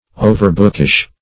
Overbookish \O"ver*book"ish\, a.
overbookish.mp3